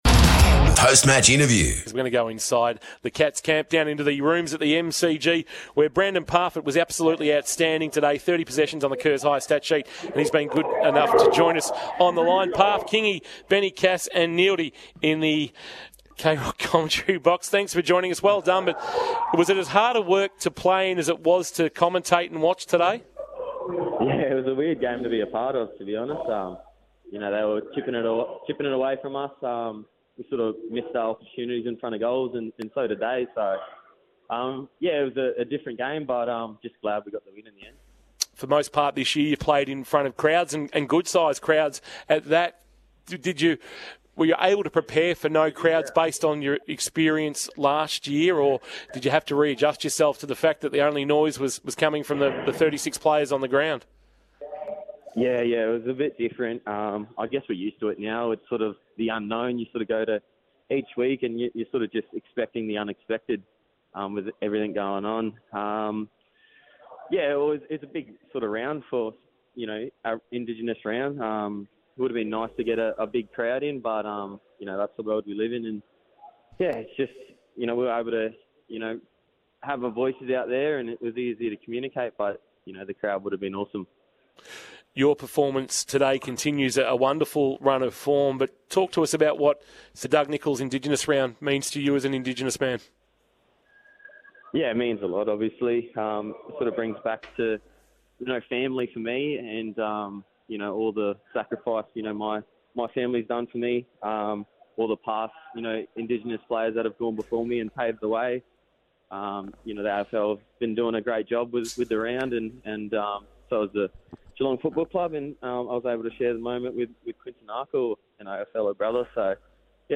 POST-MATCH INTERVIEW: BRANDAN PARFITT - Geelong